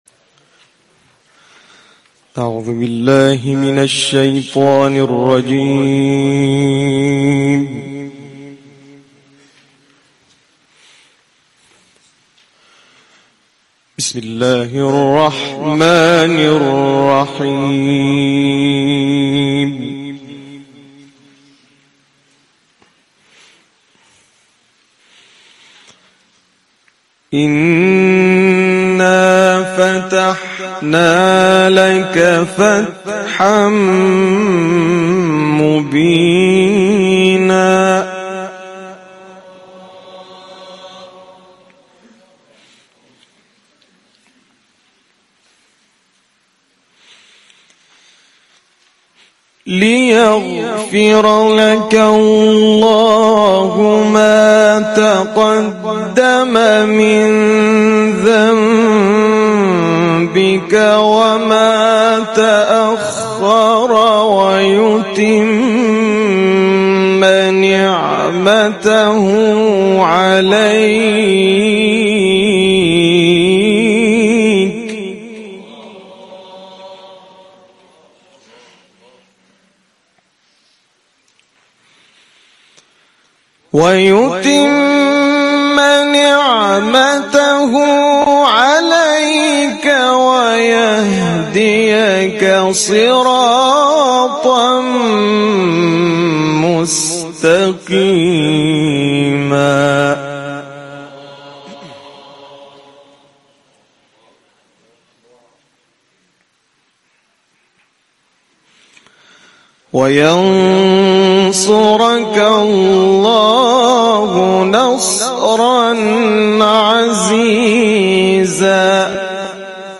تلاوت
از سوره مبارکه فتح در محفل دهکده قرآنی چارک